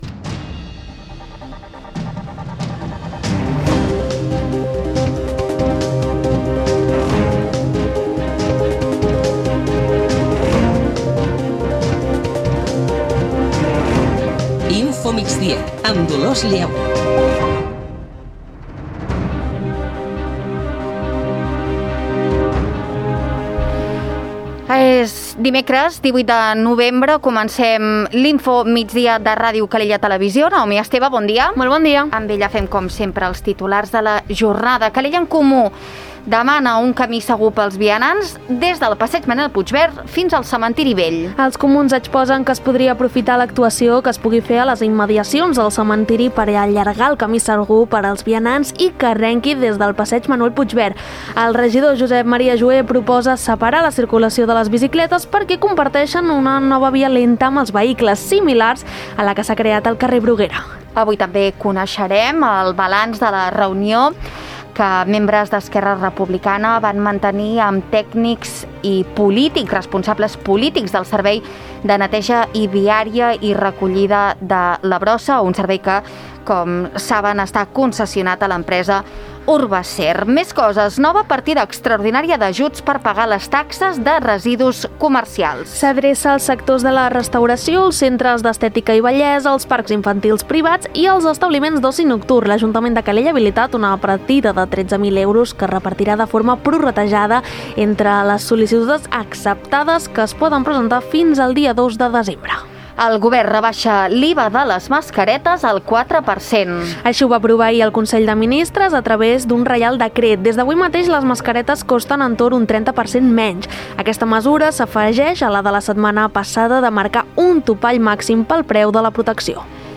Notícies d'actualitat local i comarcal.